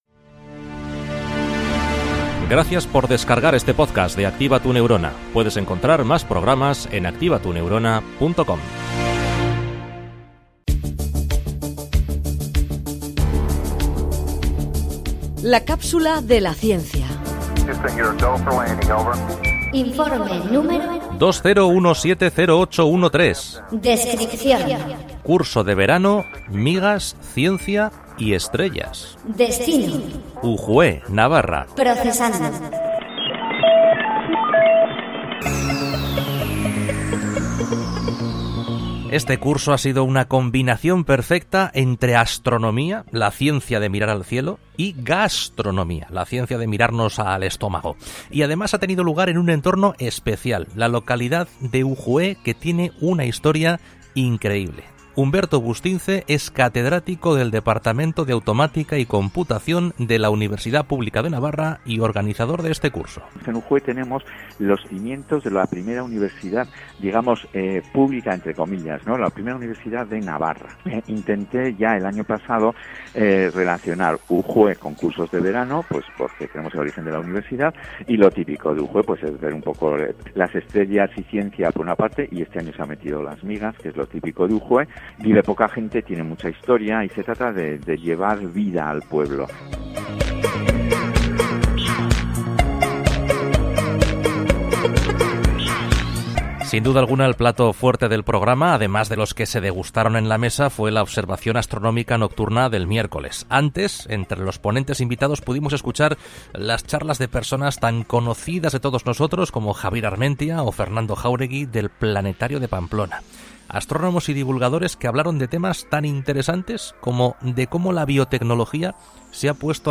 La Cápsula de la Ciencia ® es un microespacio de ciencia con trasfondo social producido por Activa Tu Neurona.
La comunicación de la ciencia es la protagonista de 3 minutos de radio en los que colaboramos con científicos punteros para contar de forma amena y sencilla los resultados de sus últimas investigaciones.